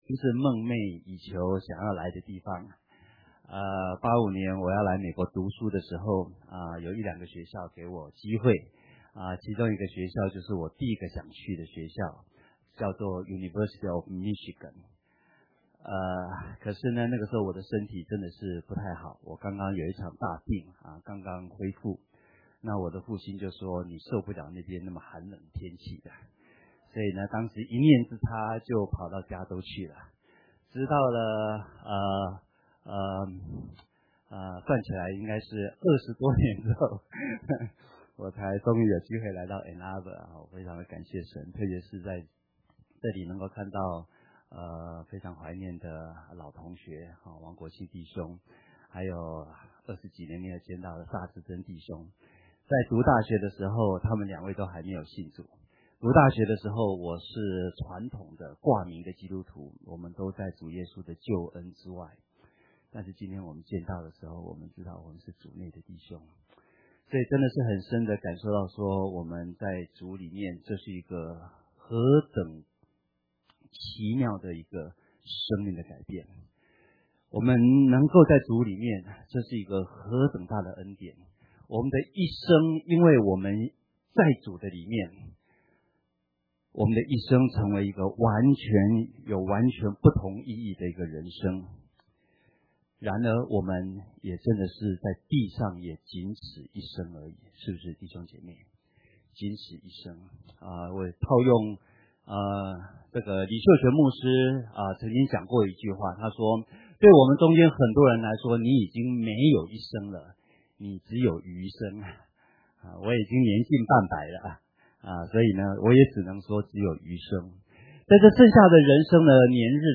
Mandarin Sermons – Page 88 – 安城华人基督教会